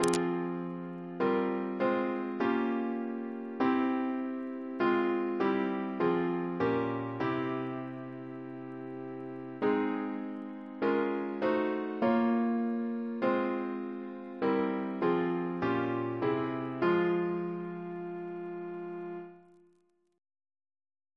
Double chant in F minor Composer: William Prendergast (1868-1933) Reference psalters: ACB: 223